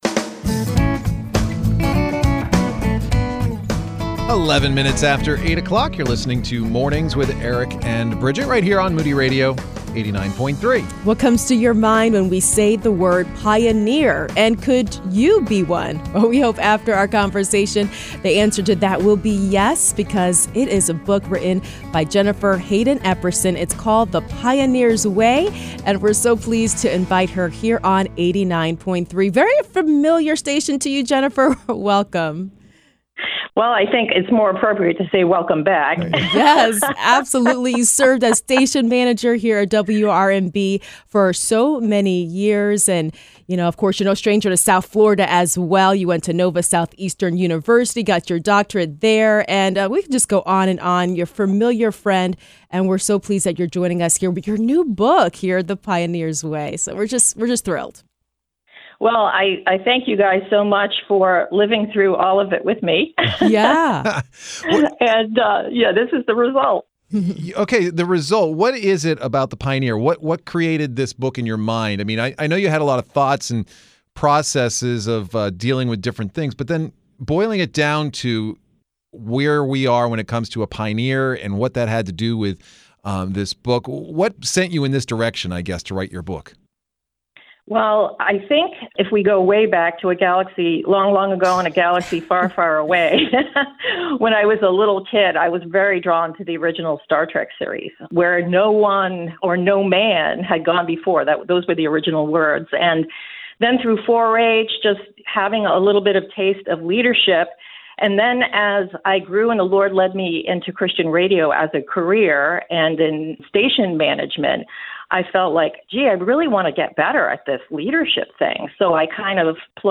Interviews – Pioneering Leadership